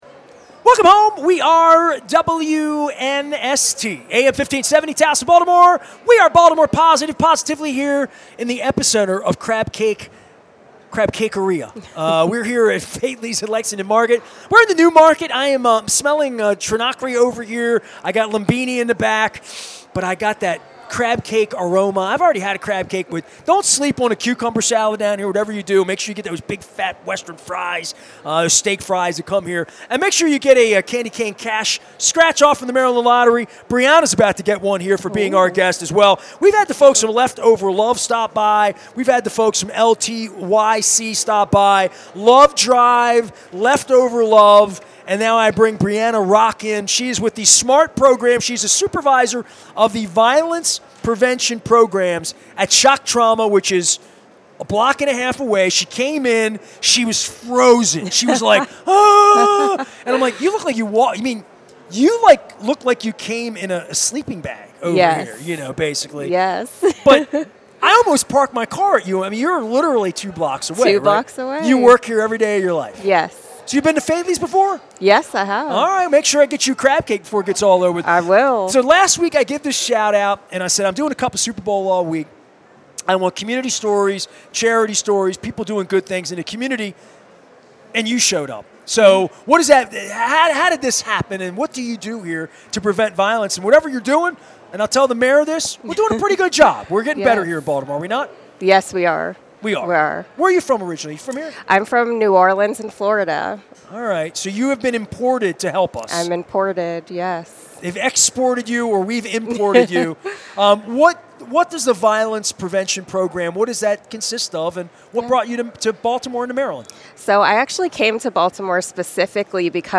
at Faidley's at Lexington Market